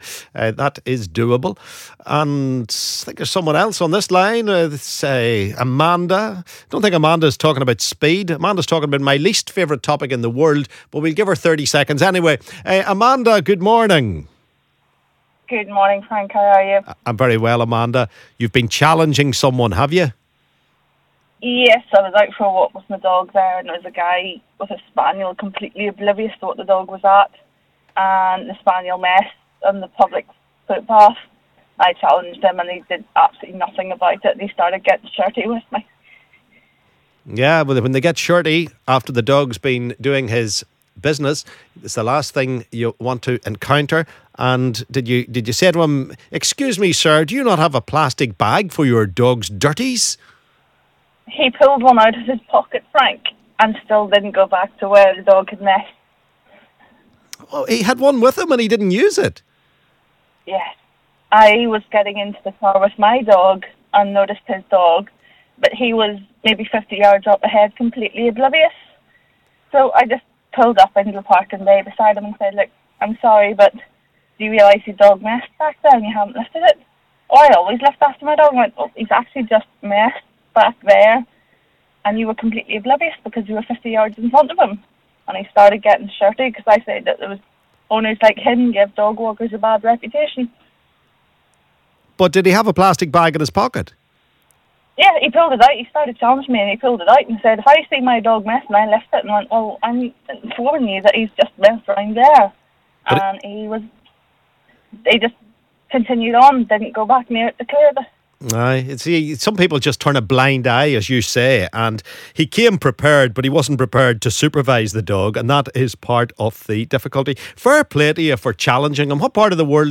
LISTEN ¦ Caller angry after confrontation with dog walker over fouling